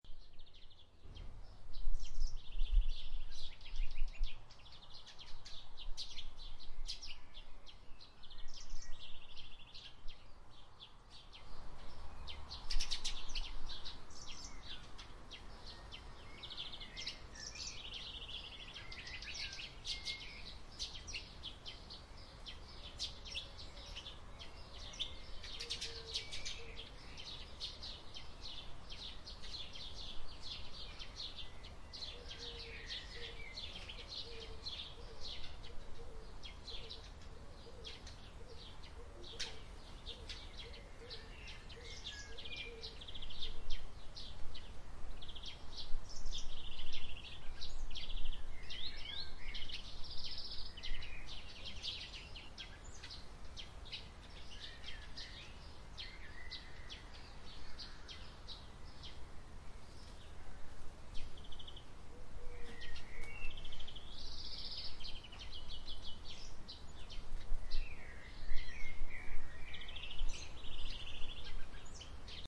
Sound Effect  (best played while reading) courtesy of Freesound Community at Pixabay